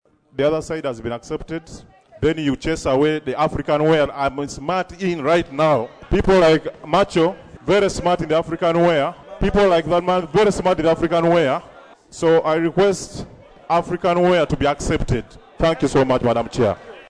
Hon. Derrick Orone (NRM, Gogonyo County, Pallisa) unsuccessfully proposed that various types of African wear be permitted in the House.